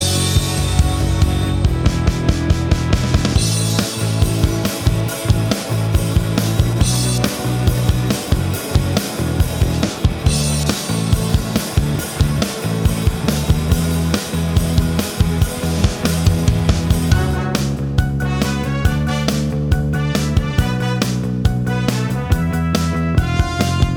No Lead Guitar Pop (2010s) 3:39 Buy £1.50